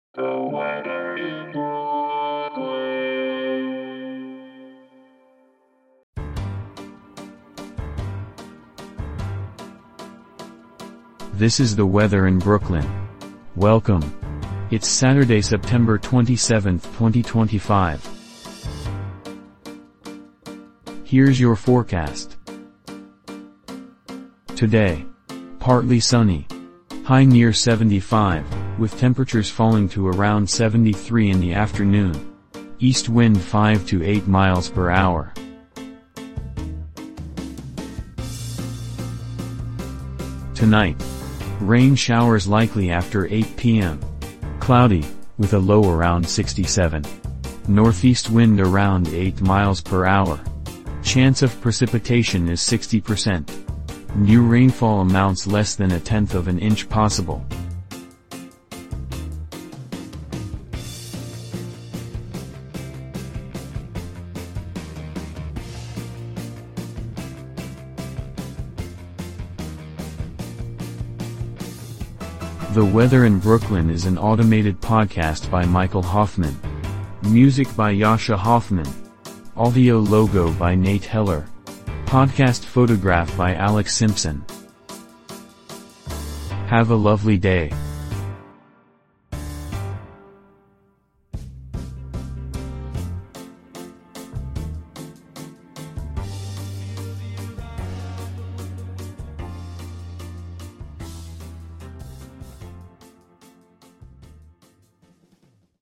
generated automatically